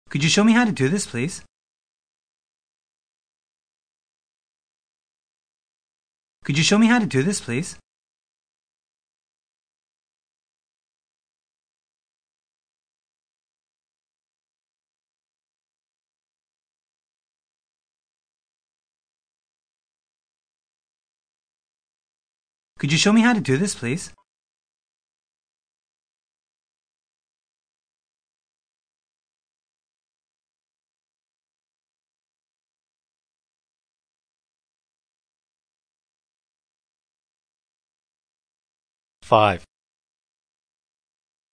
Speech Communication Dictation
Form-Focused Dictation 1: Wh. vs. Yes/No Questions (intonation patterns)